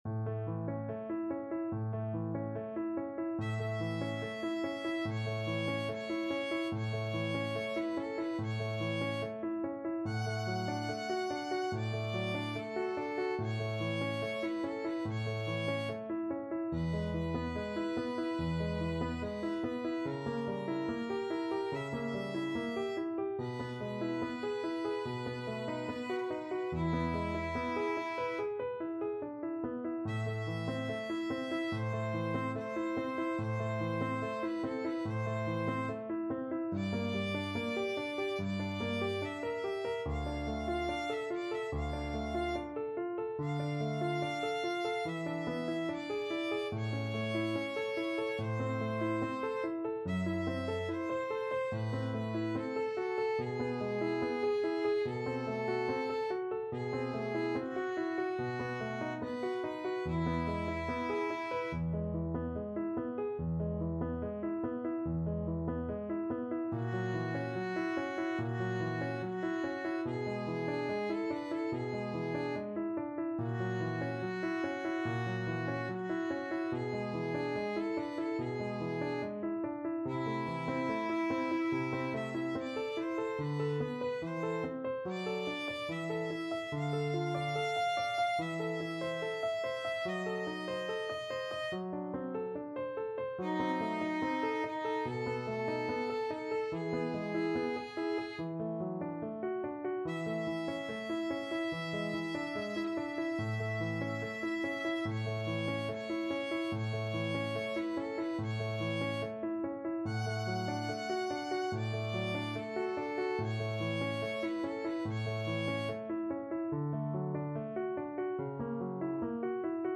Play (or use space bar on your keyboard) Pause Music Playalong - Player 1 Accompaniment reset tempo print settings full screen
A major (Sounding Pitch) (View more A major Music for Violin Duet )
Andante =72
Classical (View more Classical Violin Duet Music)